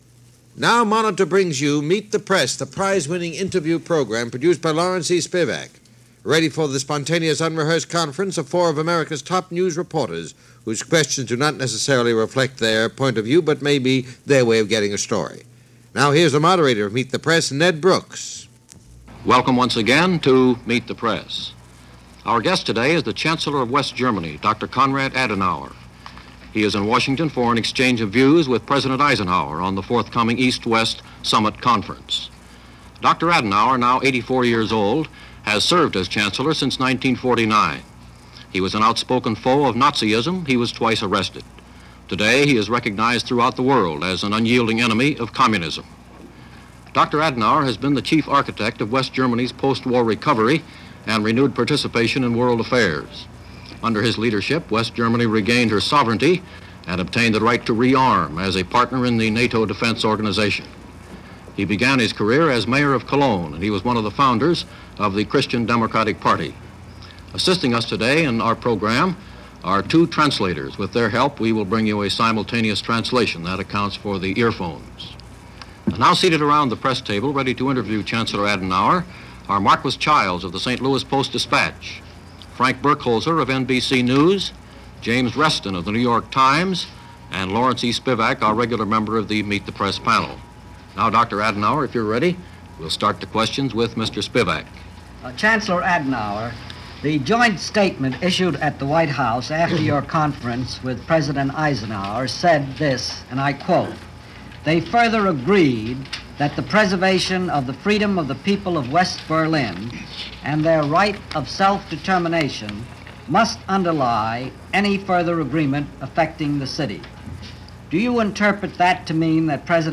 A panel interview with West German Chancellor Konrad Adenauer ahead of Paris Summit talks.
Meet-The-Press-Konrad-Adenauer-1960.mp3